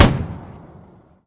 mg-shot-1.ogg